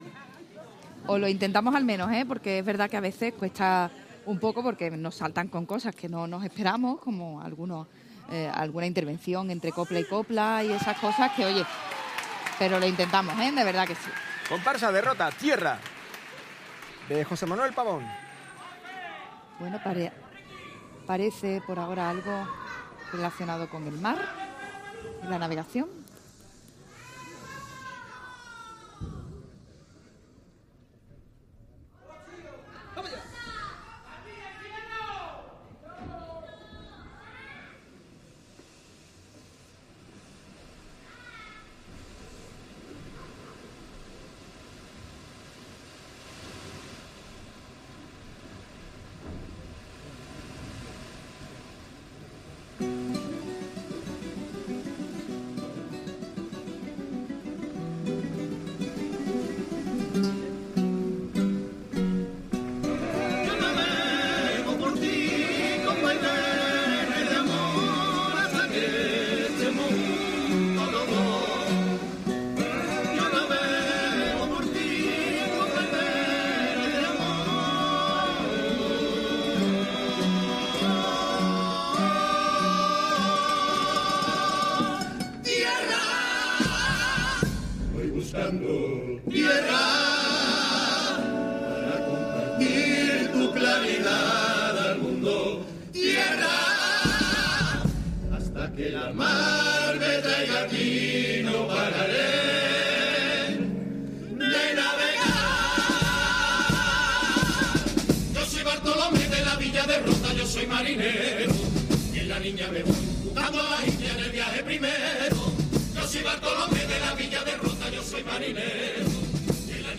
Escucha y descarga el audio de Tierra de Preliminares del Concurso Oficial de Agrupaciones del Carnaval de Cádiz (COAC) 2023 en formato MP3 y de manera gratuita
Disfruta de la actuación brindada por la Comparsa Tierra en la fase preliminares del COAC 2023.